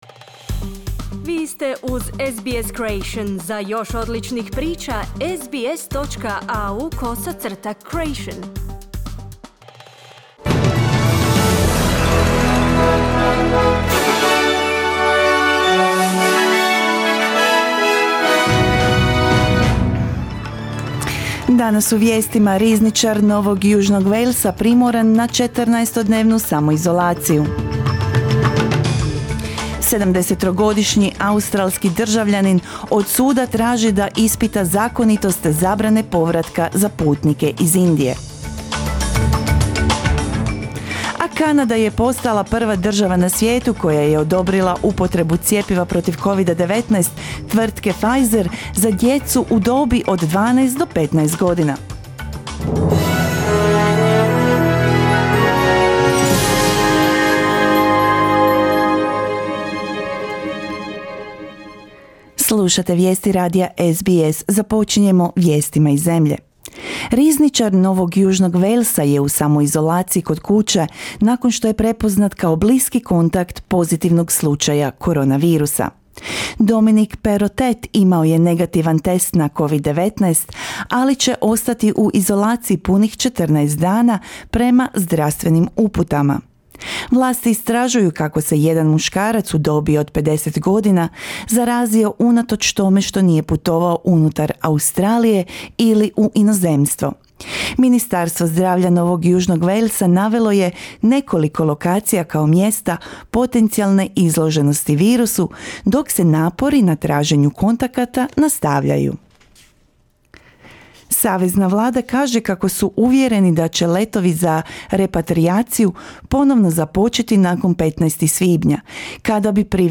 Vijesti radija SBS na hrvatskom jeziku.